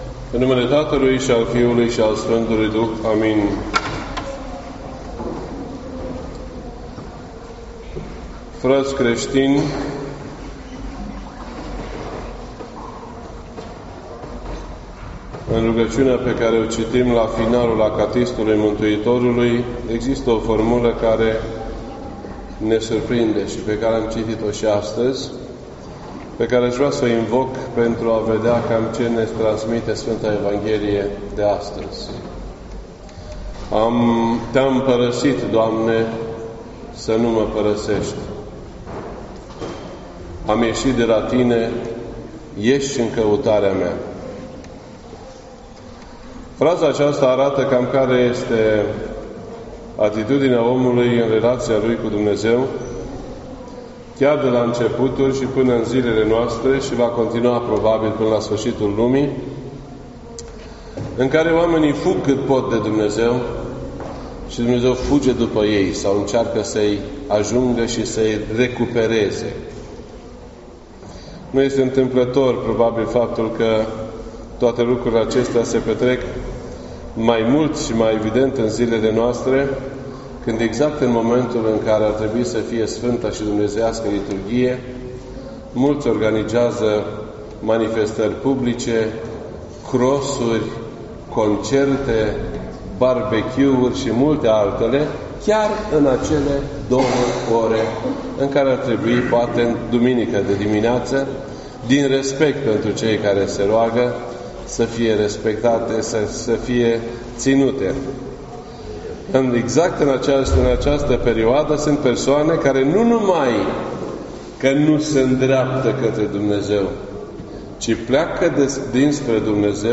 This entry was posted on Sunday, July 1st, 2018 at 1:32 PM and is filed under Predici ortodoxe in format audio.